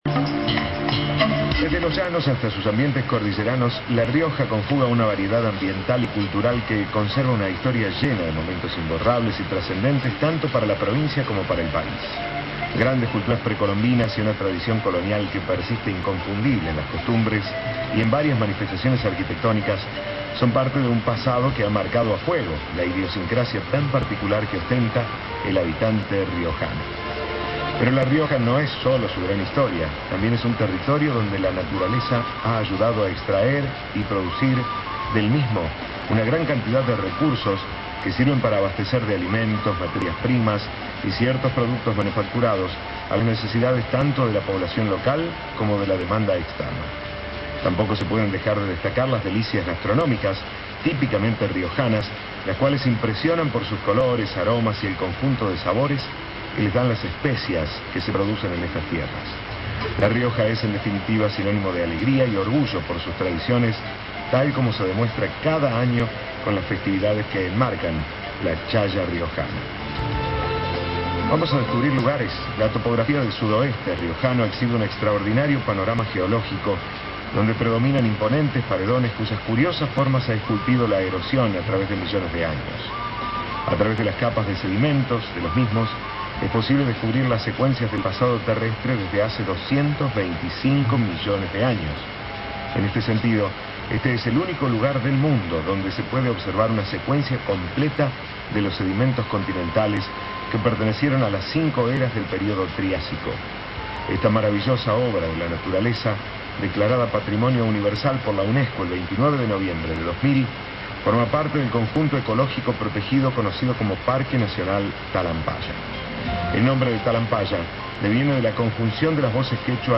El programa sabatino Tiempo Compartido, que se emite por radio Cadena 3 Argentina, difundió este sábado los atractivos turísticos de la provincia.